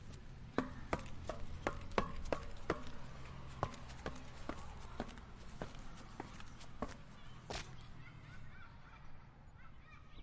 描述：攀登金属台阶为一个大金属幻灯片
Tag: 楼梯 金属楼梯